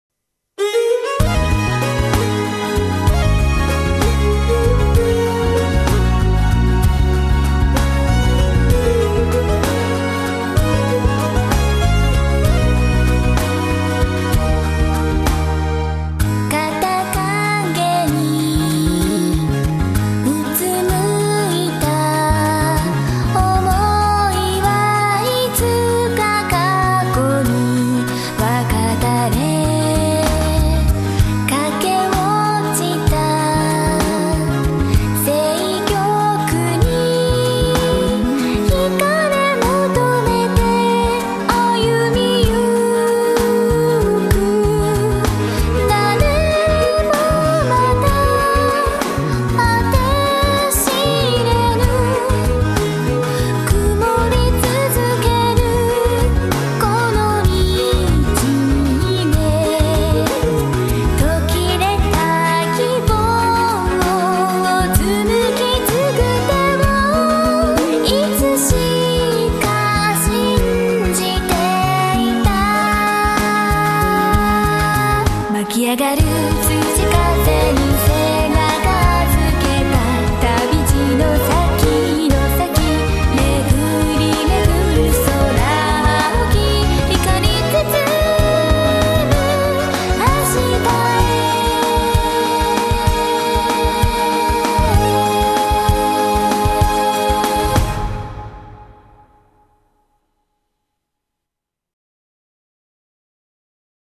仮想SRPGサウンドトラック
オリジナルヴォーカル2トラック＋オリジナルインスト18トラック、手焼きCD−R
オープニング主題歌